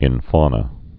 (ĭnnə)